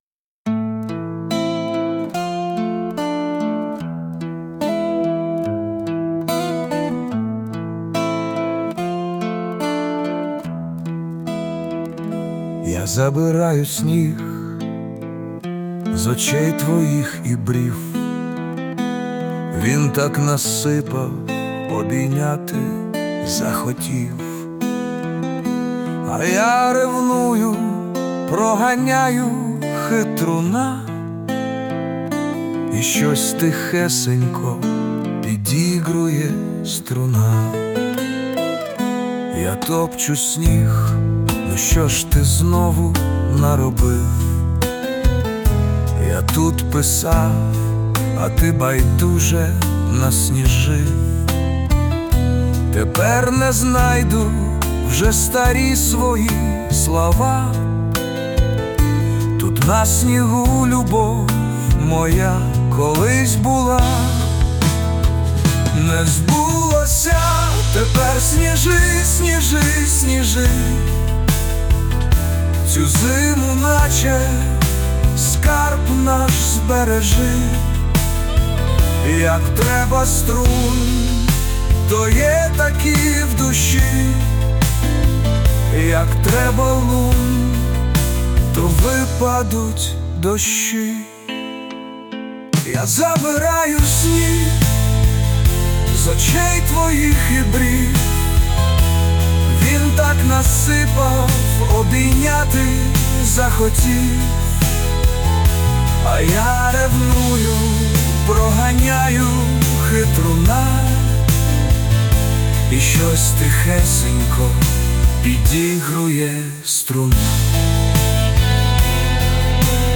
Музичний супровід створено з допомогою ШІ
СТИЛЬОВІ ЖАНРИ: Ліричний